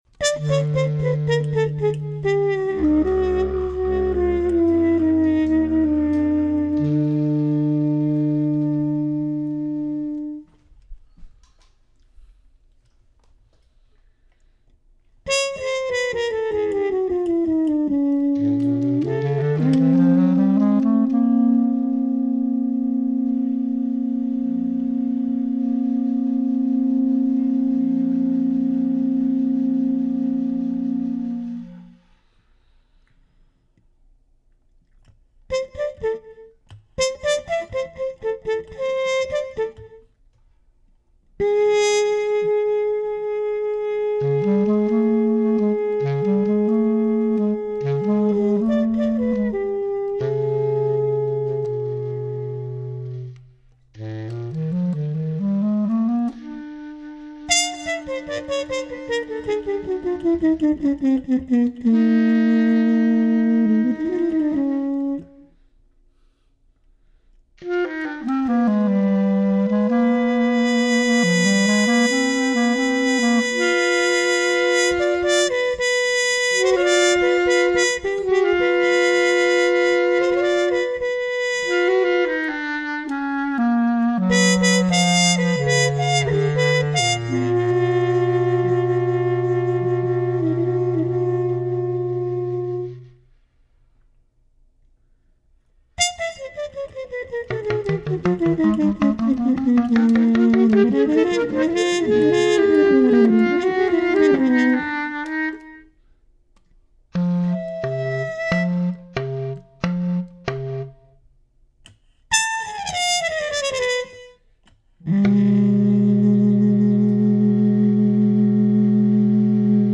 Recorded live at the Maid’s Room, NYC April 7, 2011
alto clarinet
bass clarinet
trumpet
Stereo (Pro Tools)